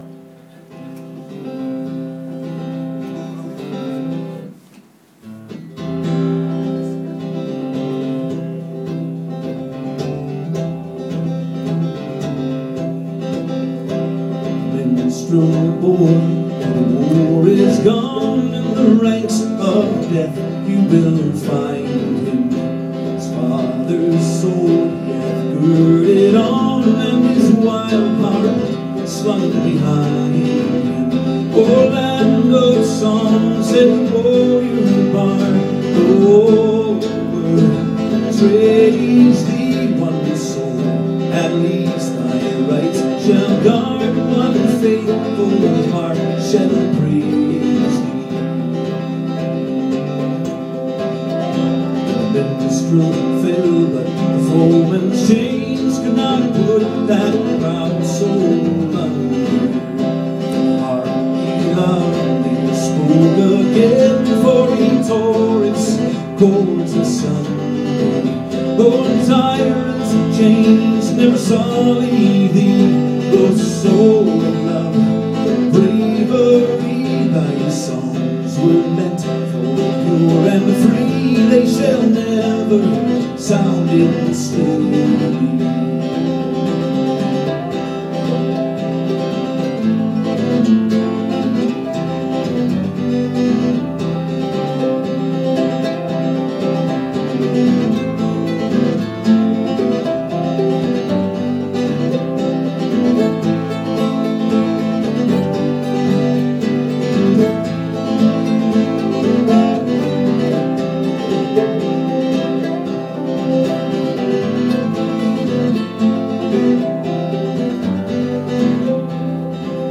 at the Monroe County History Center